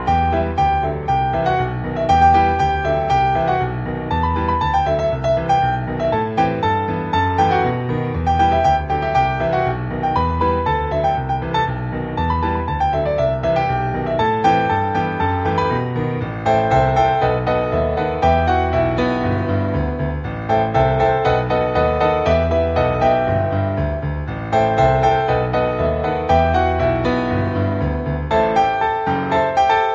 • Key: E Major
• Instruments: Piano solo
• Genre: Pop, Film/TV